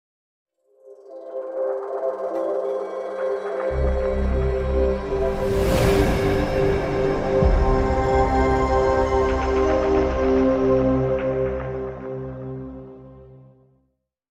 PlayStation 5 Startup